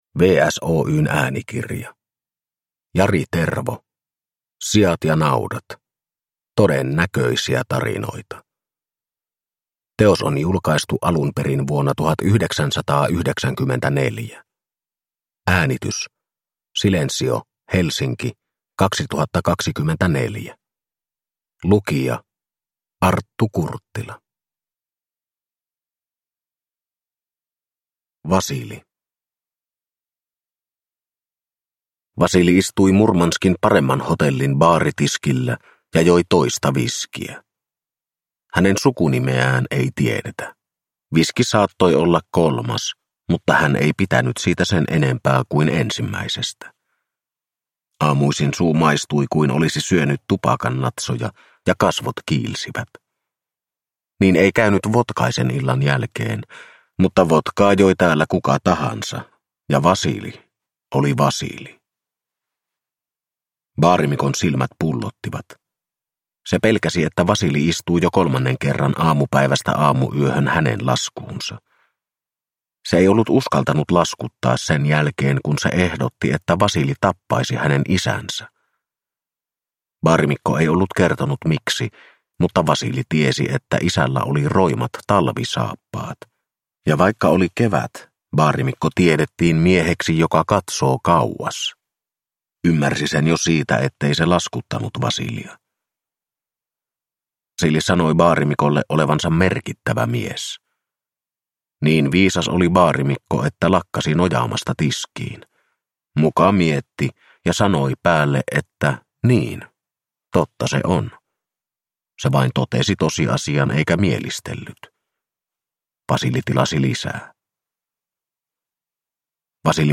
Siat ja naudat – Ljudbok